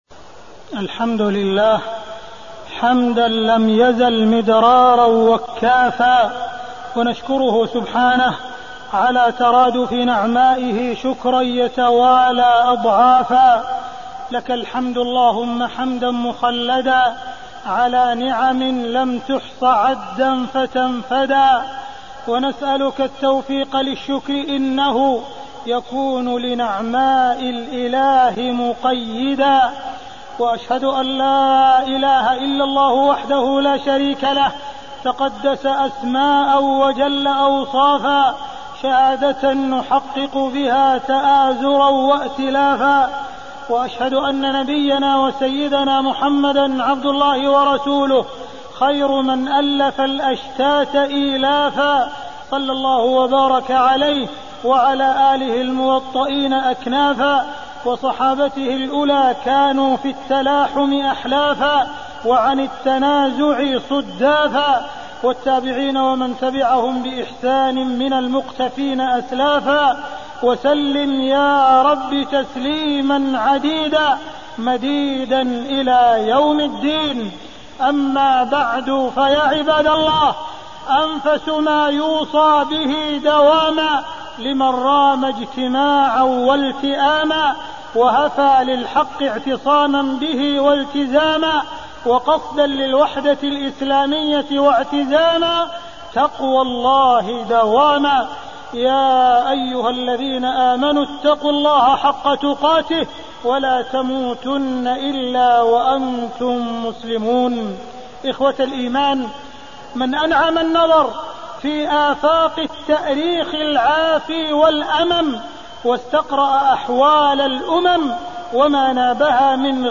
تاريخ النشر ١٣ ربيع الثاني ١٤٣٢ هـ المكان: المسجد الحرام الشيخ: معالي الشيخ أ.د. عبدالرحمن بن عبدالعزيز السديس معالي الشيخ أ.د. عبدالرحمن بن عبدالعزيز السديس وجوب الإجتماع ونبذ الفرقة